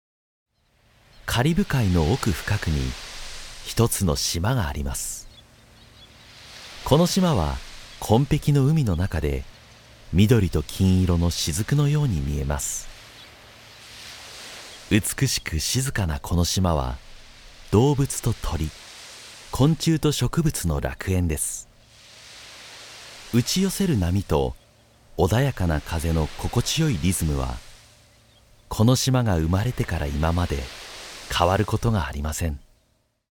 una voz de barítono enérgica y confiable
Muestras de voz nativa
Audiolibros